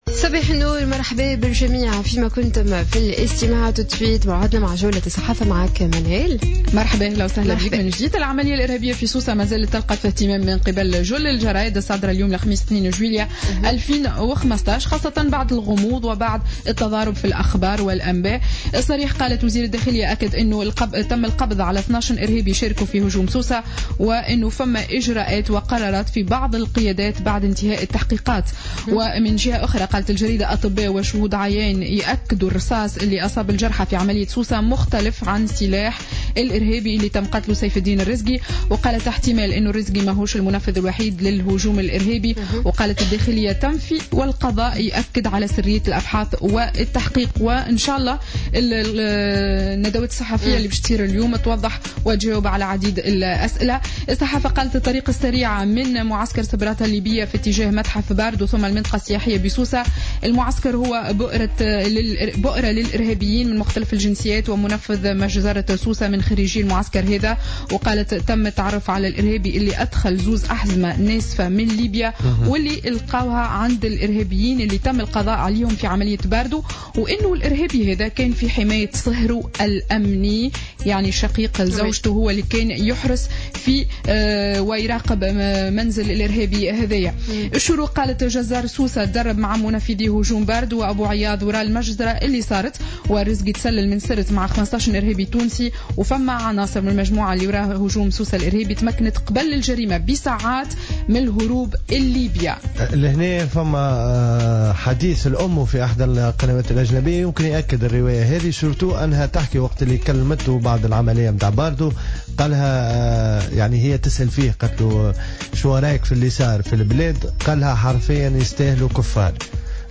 Revue de presse du jeudi 02 juillet 2015